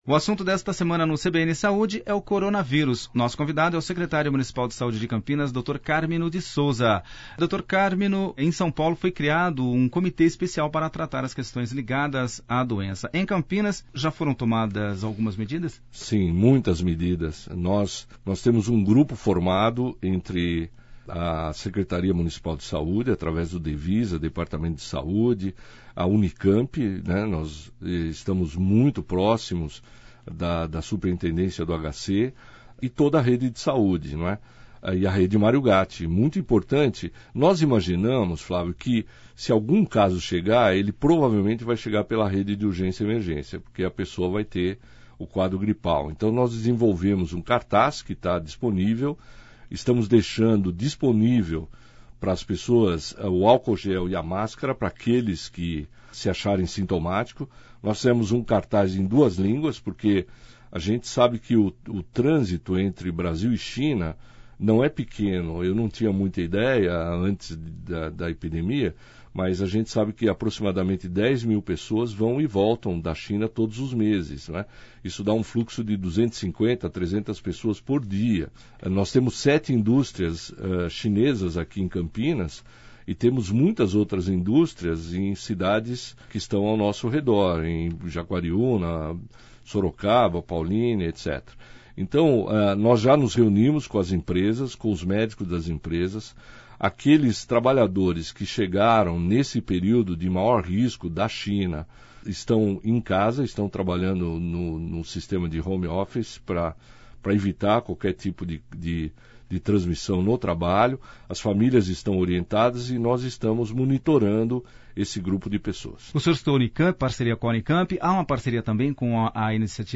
O coronavírus é uma preocupação mundial e a as autoridades de todo o Brasil se movimentam para minimizar os efeitos da epidemia. O secretário de saúde de Campinas, Cármino de Sousa, explica todos os detalhes da doença e as medidas tomadas pelo município para combater o novo vírus.